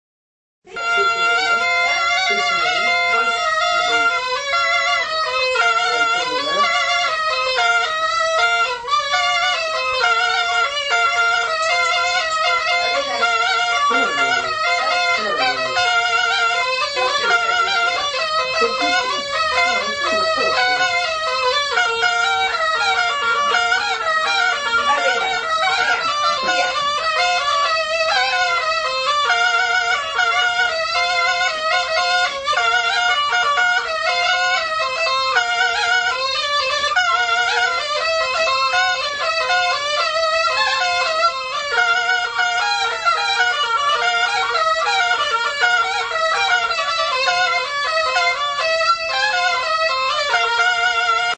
Music instrumentsAlboka
Aerophones -> Reeds -> Single fixed (clarinet)
Recorded with this music instrument.
Klarinete bikoitza da.